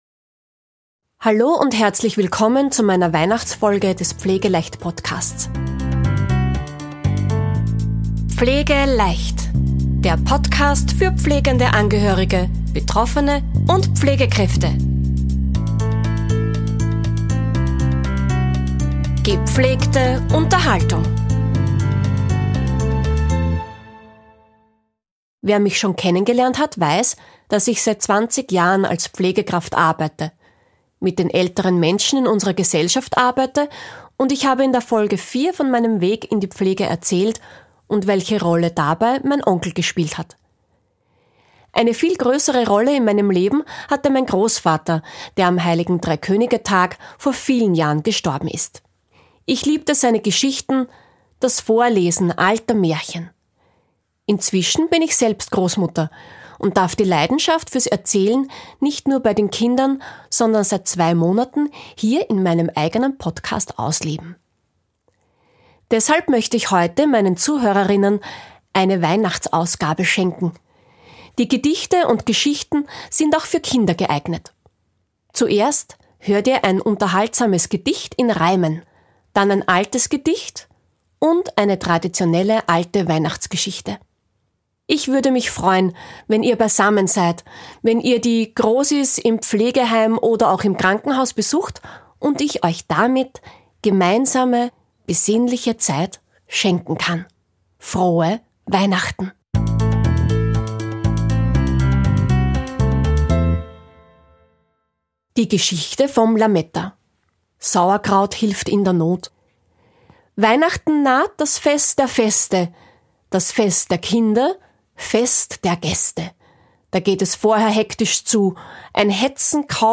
In dieser Weihnachts-Episode, werdet ihr dazu eingeladen, gemeinsam drei Geschichten zu lauschen.